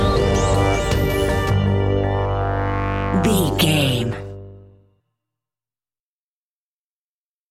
royalty free music
Aeolian/Minor
ominous
dark
suspense
eerie
synthesiser
drums
electronic music